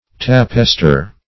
Tappester \Tap"pes*ter\, n.